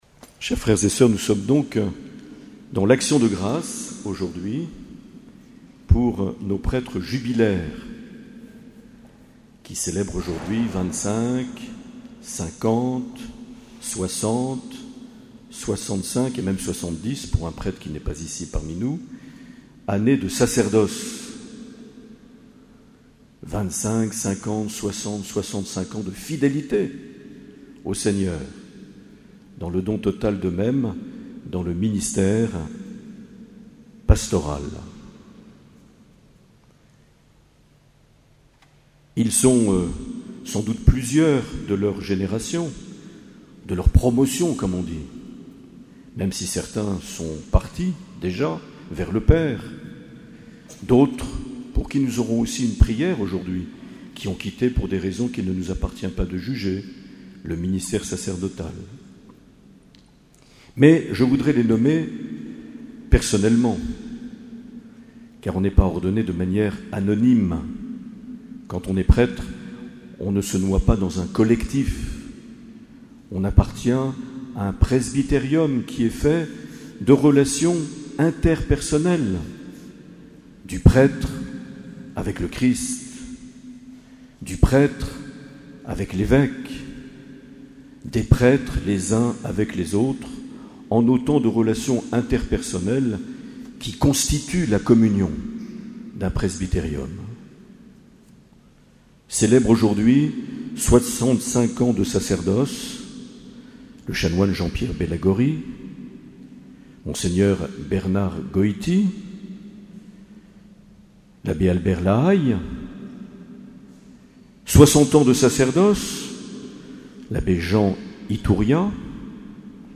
24 juin 2016 - Cathédrale de Bayonne - Messe avec les prêtres jubilaires et admission des candidats au sacerdoce
Une émission présentée par Monseigneur Marc Aillet